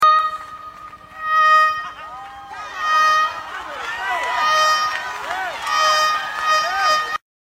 Woman Singing Fly Sounds meme sound effects free download